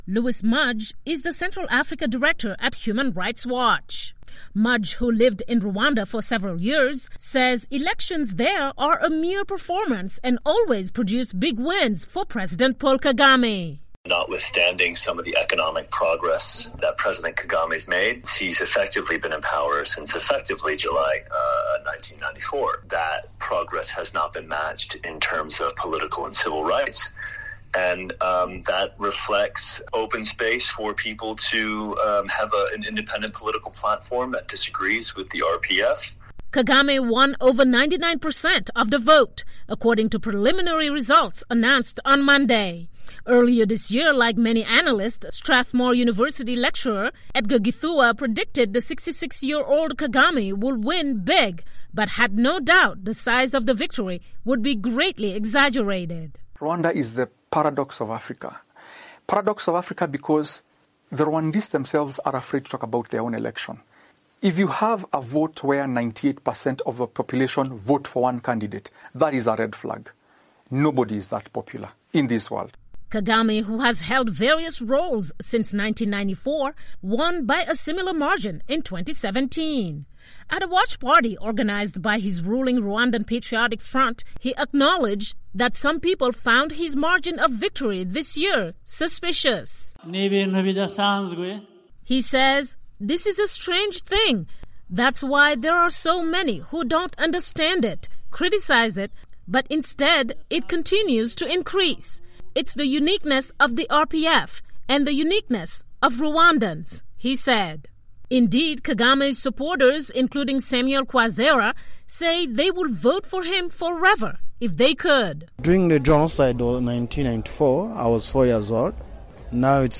spoke to analysts regarding Rwanda’s political landscape and files this report from Rwanda’s capital, Kigali